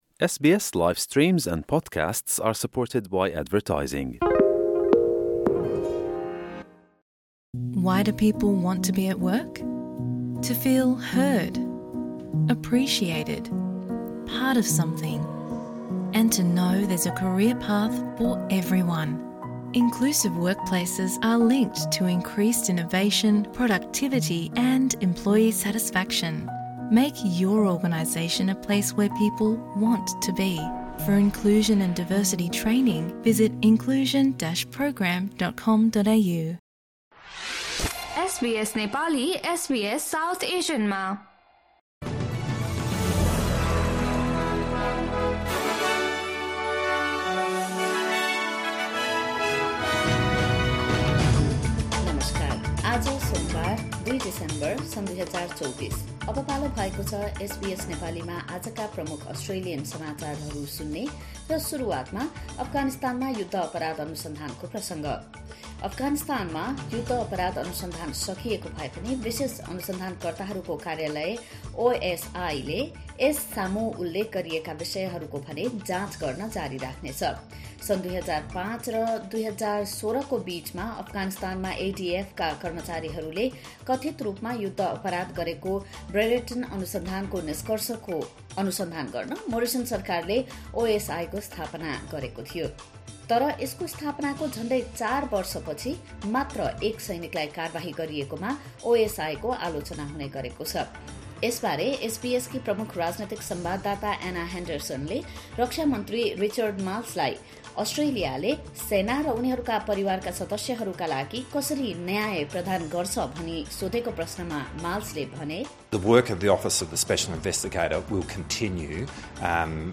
एसबीएस नेपाली प्रमुख अस्ट्रेलियन समाचार: सोमवार, २ डिसेम्बर २०२४